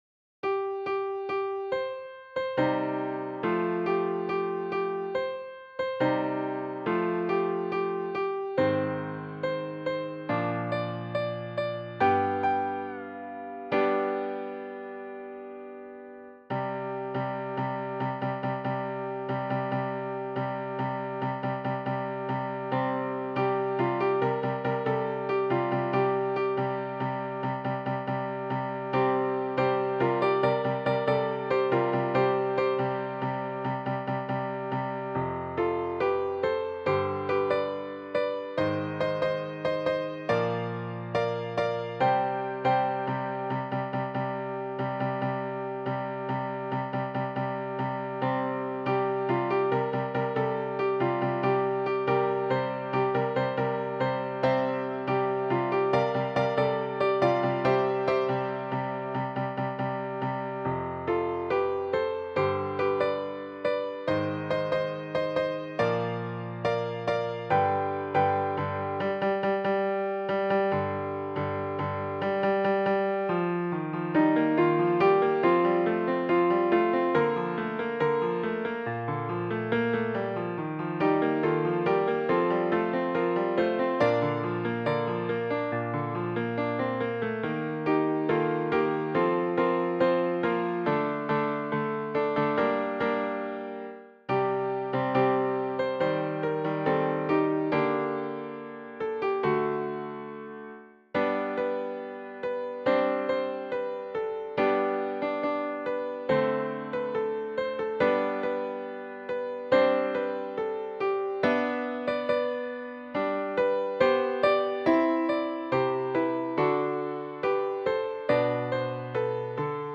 4-part mixed choir, SATB A Cappella
宗教音樂